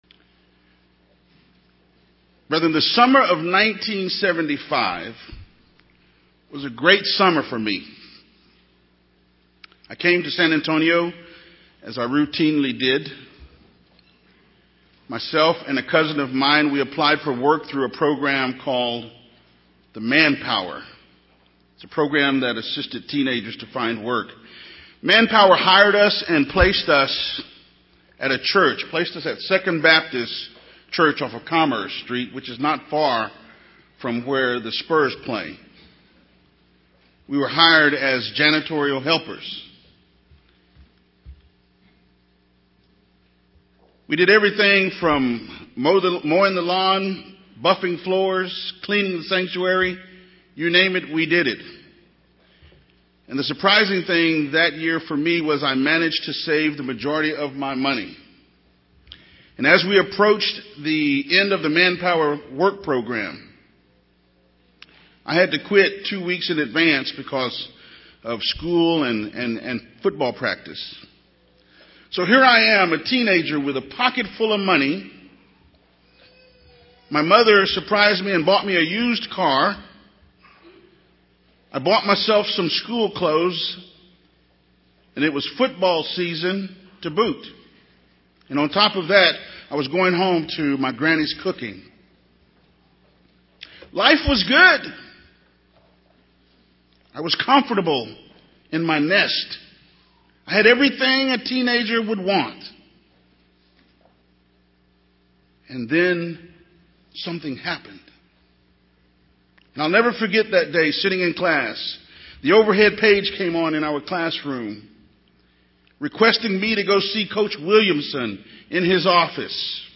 Given in San Antonio, TX